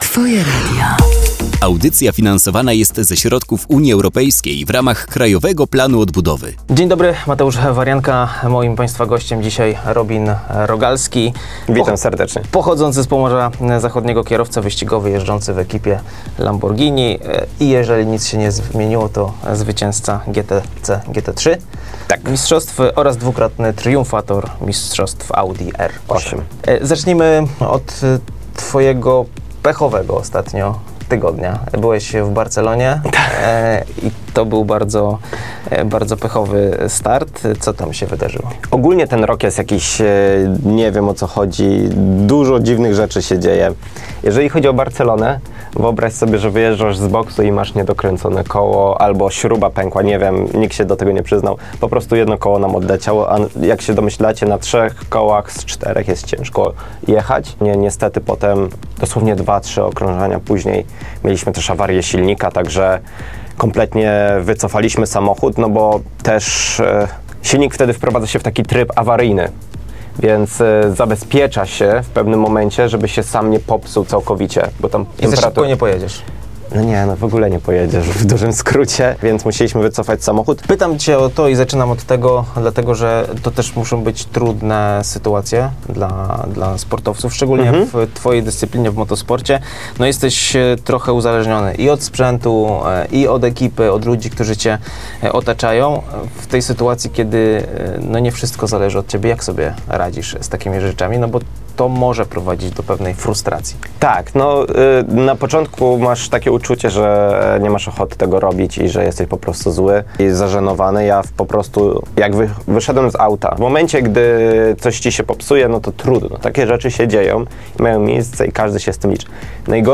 Wywiad, który mogliście usłyszeć na antenie Twojego Radia, jest już dostępny w formie podcastu!